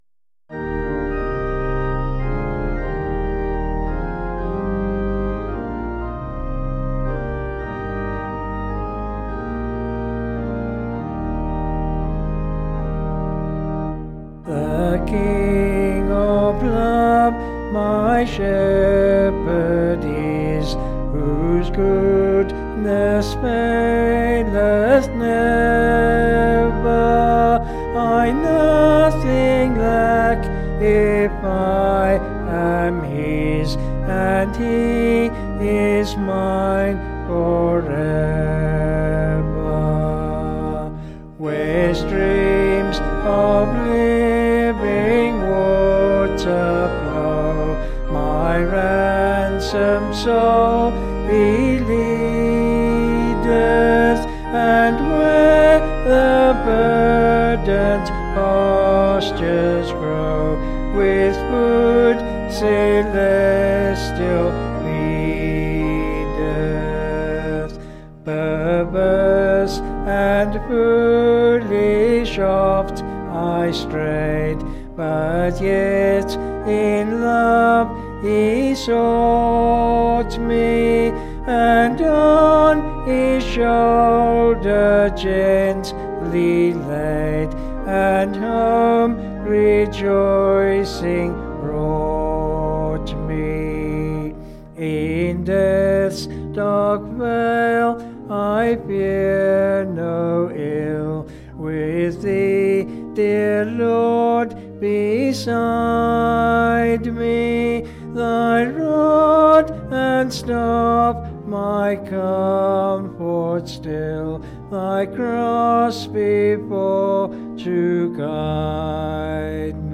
(BH)   6/Eb
Vocals and Organ   265kb Sung Lyrics